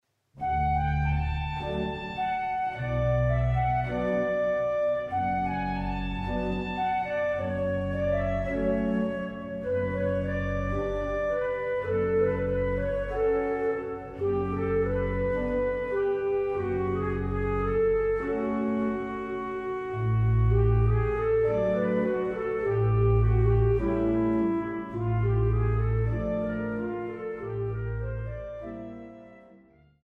Marienmusik aus dem evangelischen Dom St. Marien zu Wurzen
Orgel